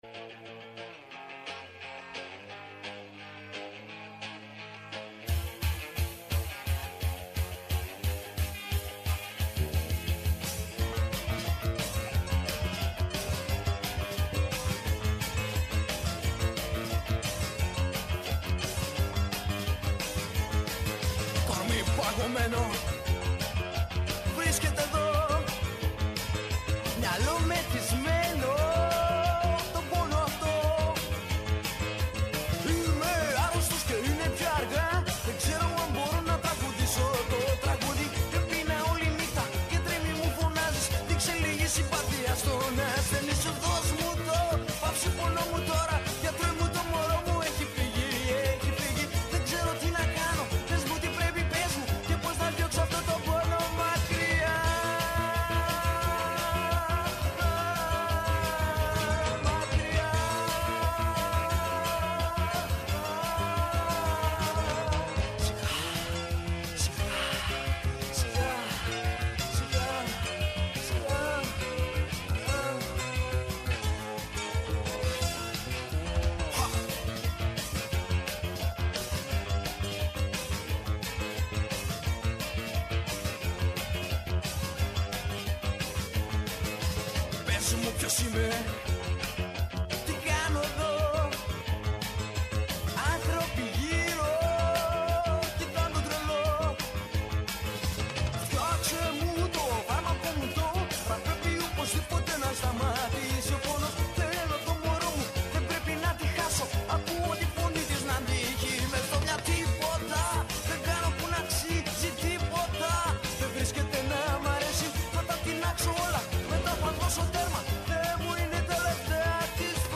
ΔΕΥΤΕΡΟ ΠΡΟΓΡΑΜΜΑ Μουσική Συνεντεύξεις